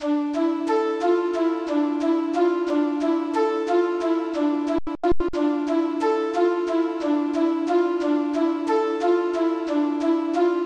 描述：调 C 阿拉伯语，bpm 160
Tag: 160 bpm Trap Loops Synth Loops 6.06 MB wav Key : C FL Studio